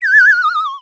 LOTUS_FLUTE_Whistle_Down_Wobble_02_mono.wav